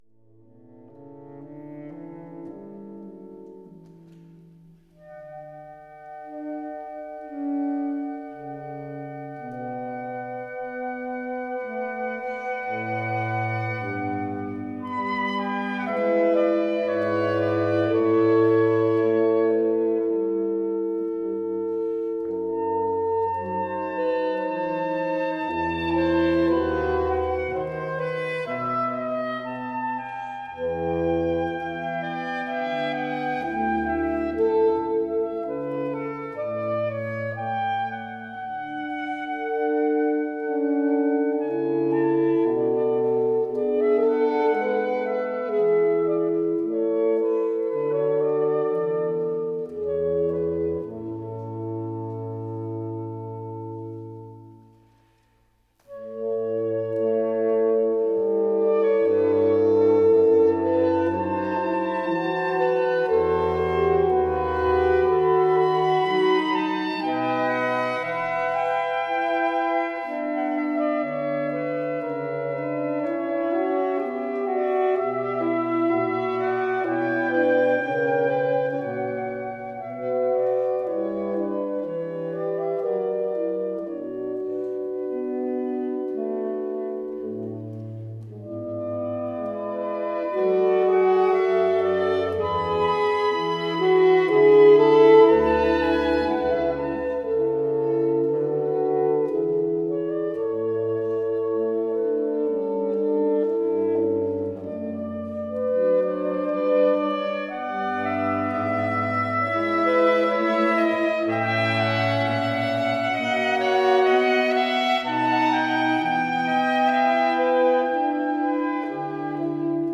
SSax, ASax, TSax, BSax